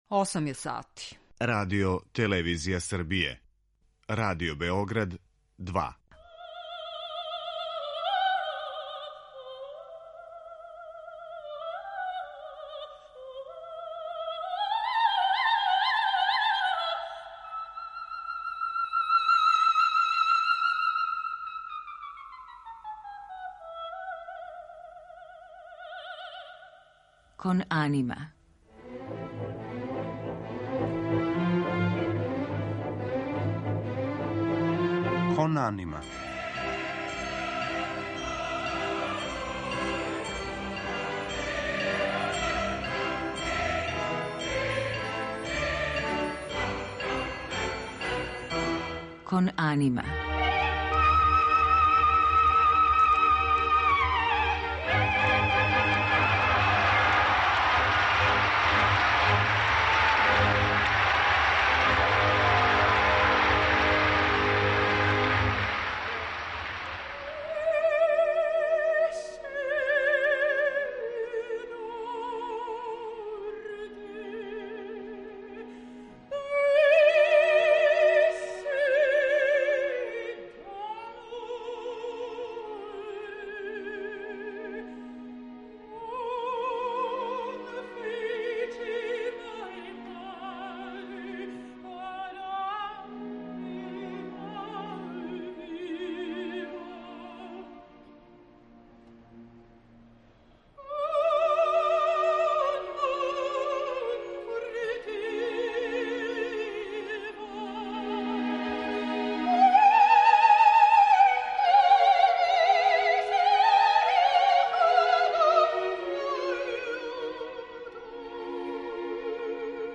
У центру наше пажње поново ће бити неки изузетни певачи о којима смо говорили, као што су перуански тенор Хуан Дијего Флорес, млада норвешка сопранисткиња Лиза Давидсен, или велика певачица Џеси Норман, која је пре две године напустила животну сцену. Слушаћемо одломке емисија у којима смо говорили и о операма на текстове Александра Пушкина, о остварењу које је Антонио Вивалди посветио нашем грофу Сави Владиславићу, о студији „Опера и драма" Рихарда Вагнера, или о женским ликовима у операма. Одабрали смо и најлепше одломке из опера Чајковског, Пучинија, Мусоргског и Вердија.